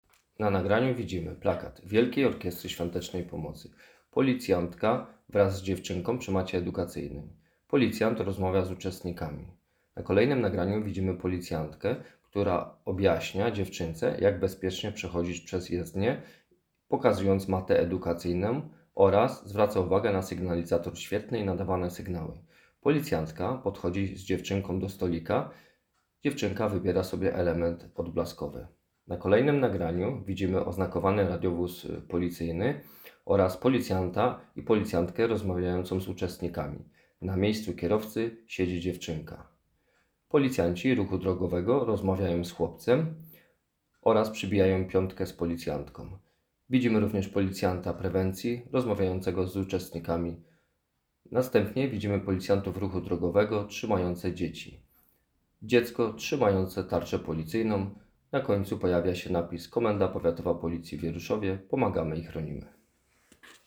Nagranie audio Audiodeskrypcja-WOSP.m4a